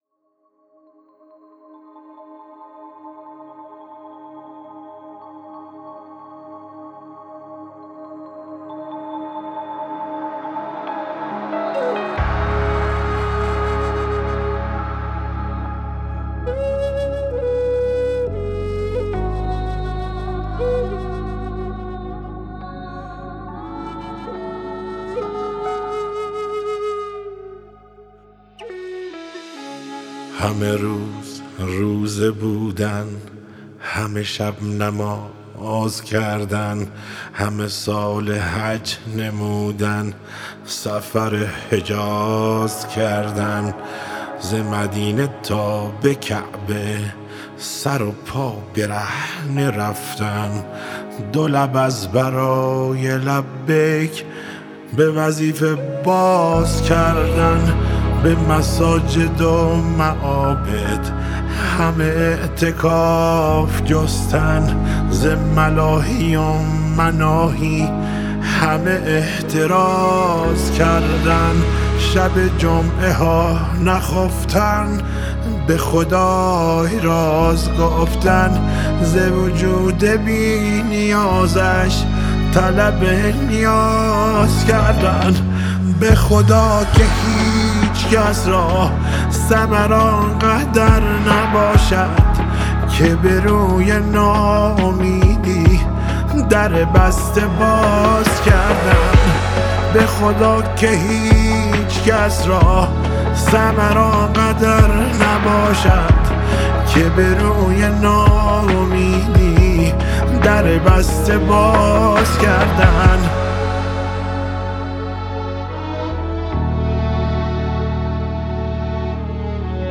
پن فلوت